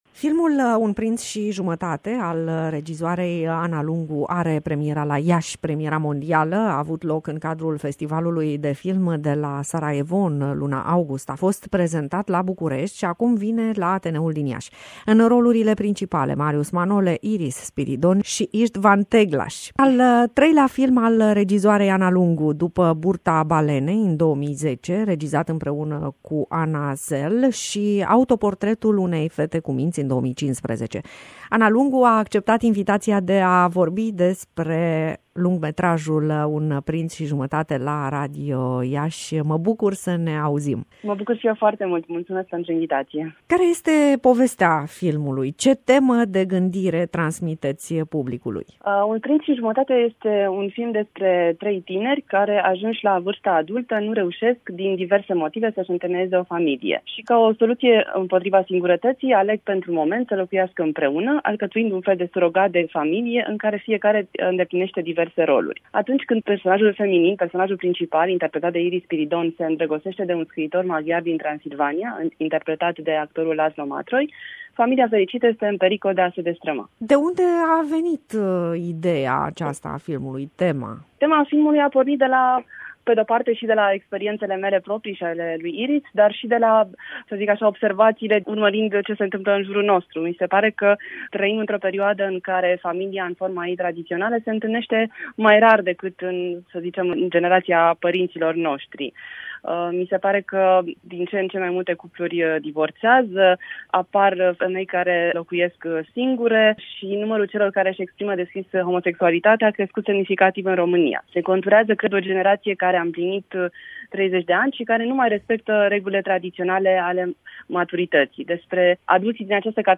„Un prinț și jumătate” vine la Iași – INTERVIU